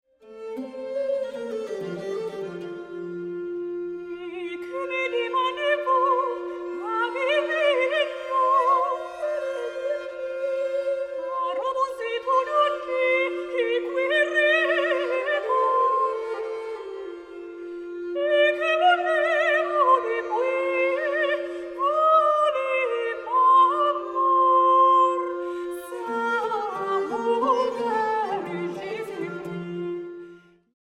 Pious trouvère songs of the 13th century
sopraano
fiideli
luuttu, saz-luuttu, sinfonia, kellot, munniharppu, isorumpu